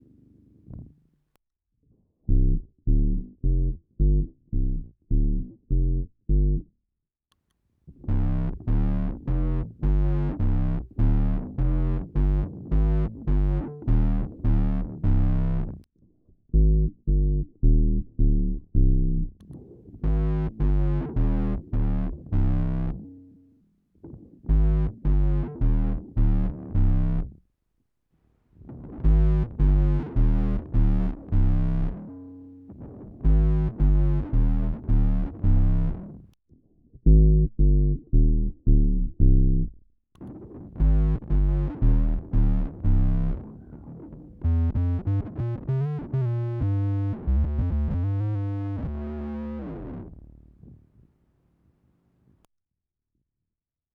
OC2 Gibson G3->Oc2->Woodruff p1 EDIT: Zuerst nur der Octaver OC-2 (mit der Jojo Mayer-Einstellung, also kein Cleansignal, nur -1OCT) -> dann halt mit dem Woodruff. Anhänge Woody+OC2.mp3 2,1 MB · Aufrufe: 182